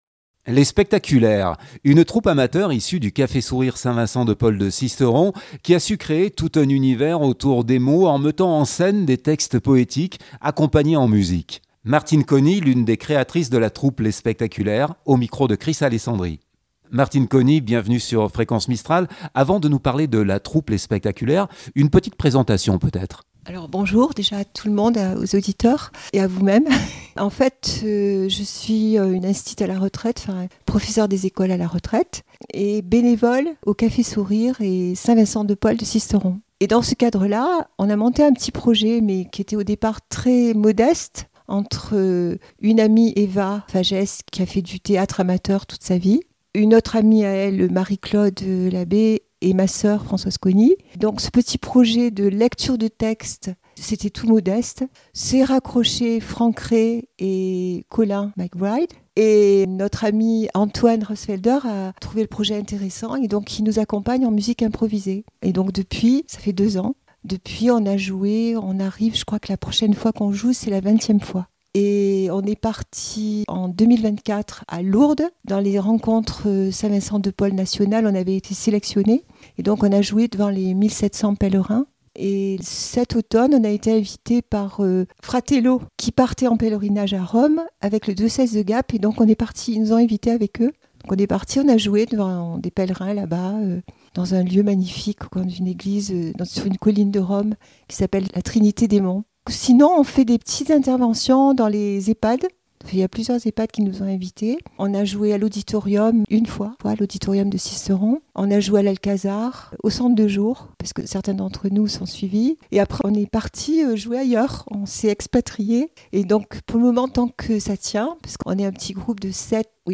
Lectures en poésies et musiques avec:«Les Spectaculaires»